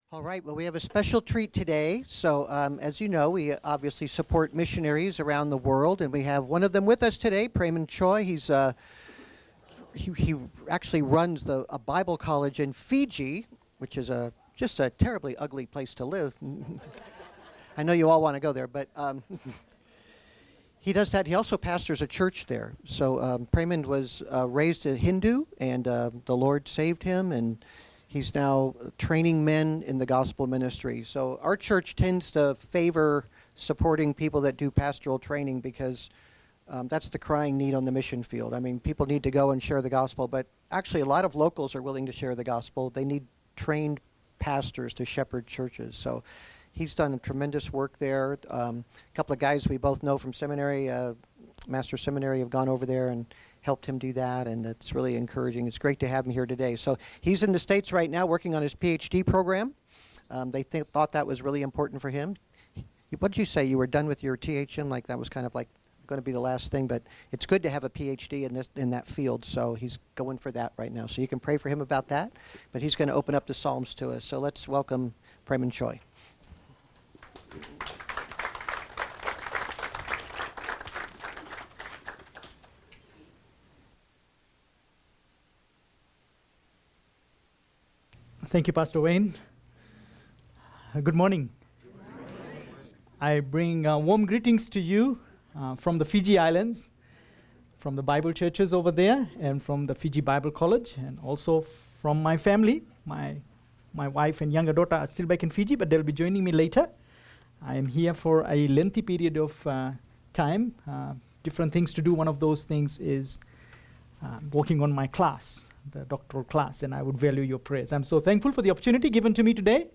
Pursuing a Triumphant Faith Guest Speaker